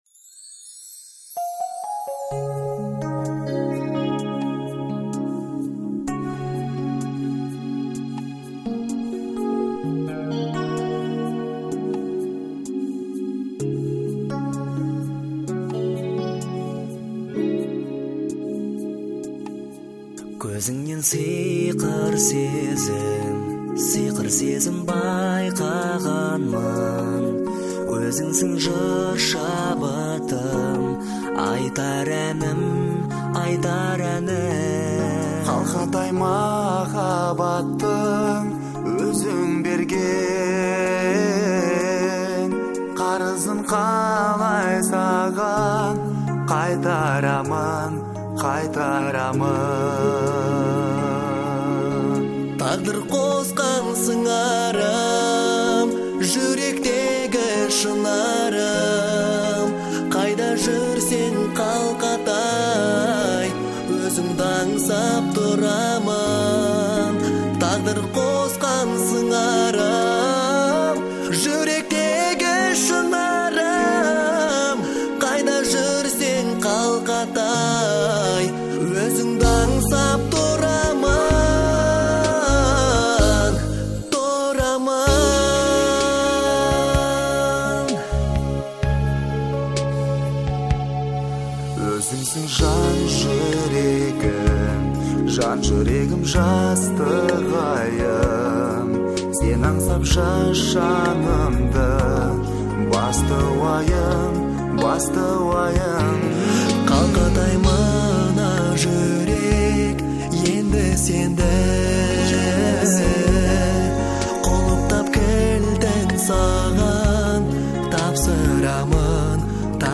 романтичная и мелодичная композиция
выполненная в жанре поп.